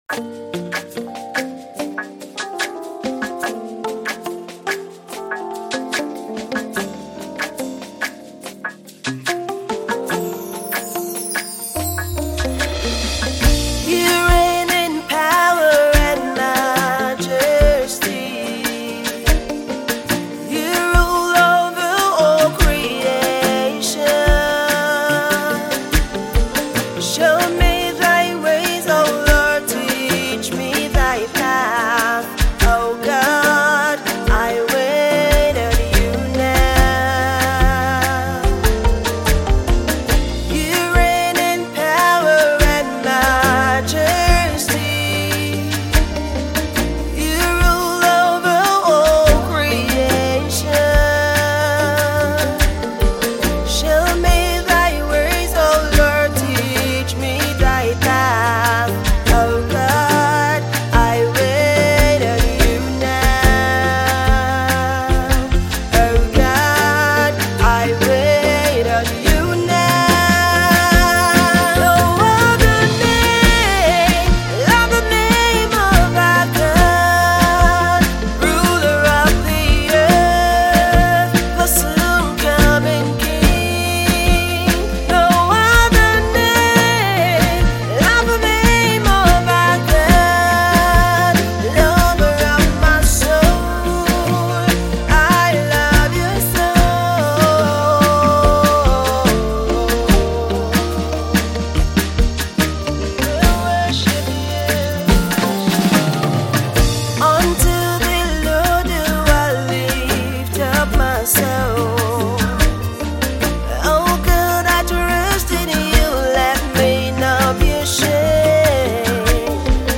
Multi-award winning UK based Nigerian gospel songstress
deep worship